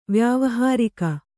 ♪ vyāvahārika